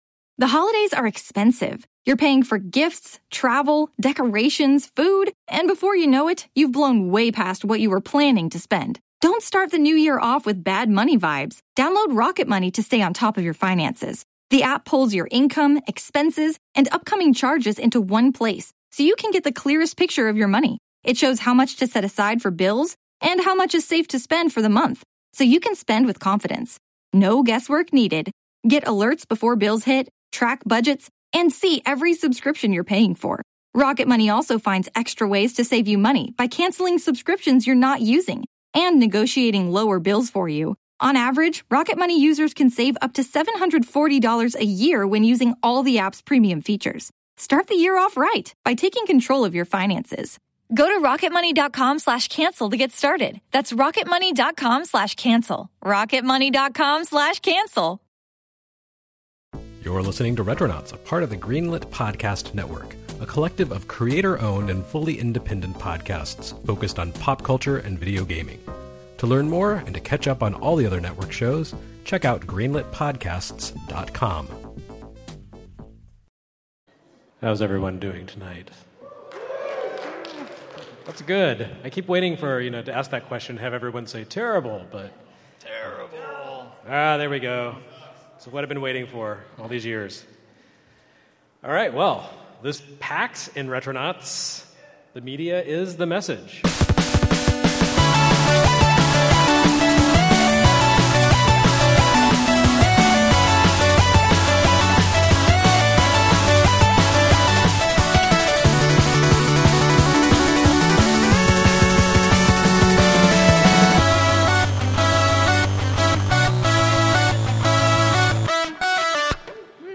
Live from PAX East